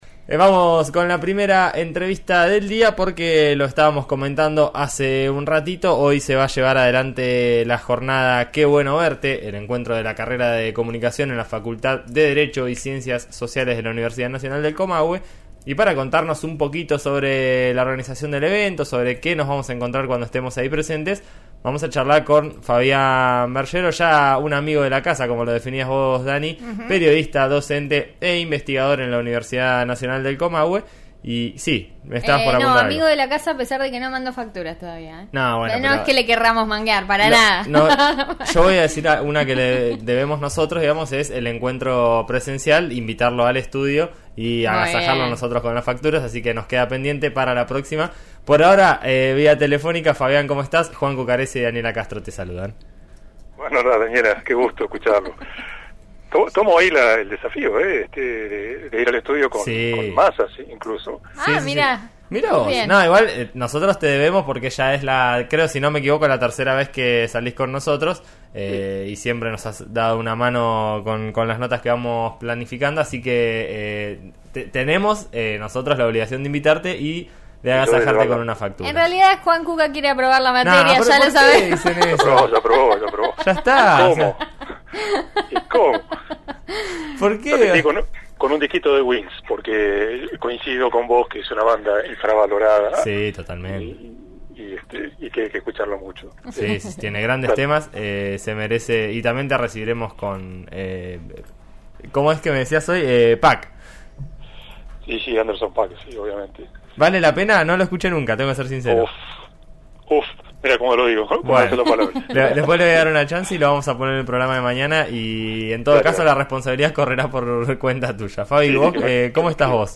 Los alumnos de la carrera de Comunicación de la Universidad Nacional del Comahue se reencontrarán (y, en algunos casos, se conocerán) en un espacio presencial, tras dos años de virtualidad. Al aire de En Eso Estamos, por RÍO NEGRO RADIO -FM 89.3 en Neuquén-